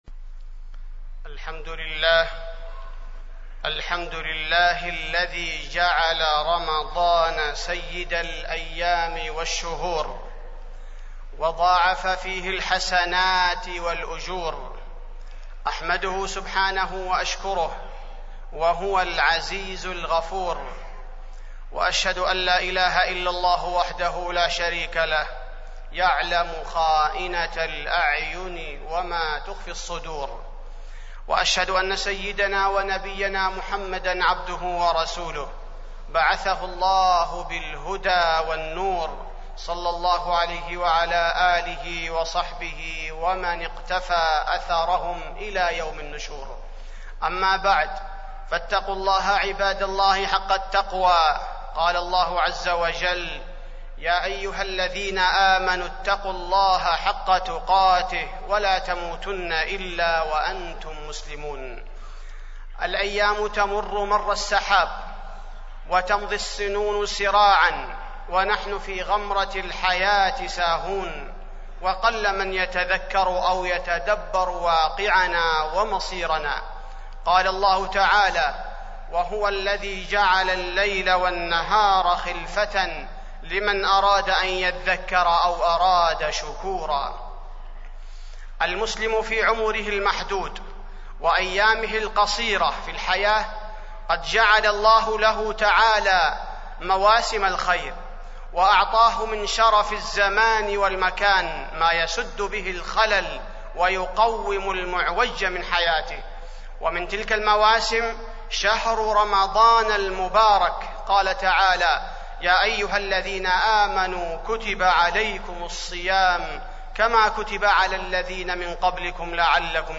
تاريخ النشر ٤ رمضان ١٤٢٦ هـ المكان: المسجد النبوي الشيخ: فضيلة الشيخ عبدالباري الثبيتي فضيلة الشيخ عبدالباري الثبيتي شهر رمضان وفضائله